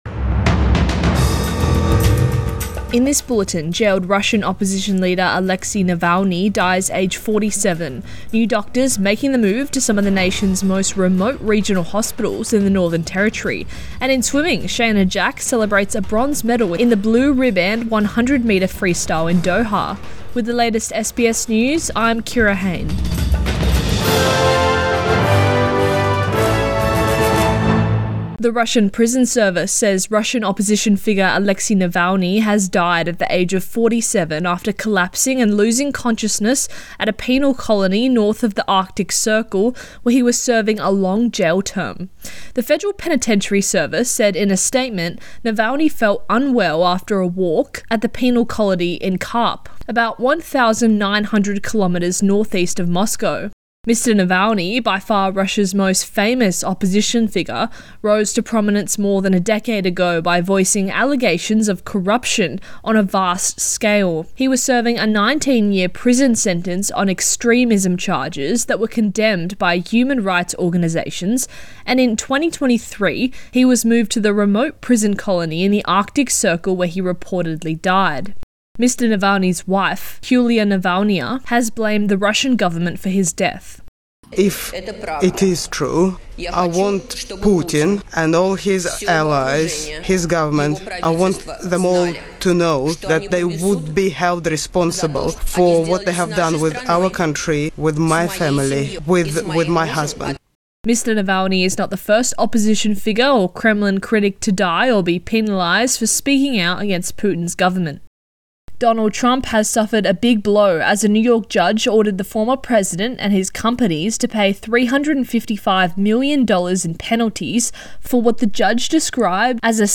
Midday News Bulletin 17 February 2024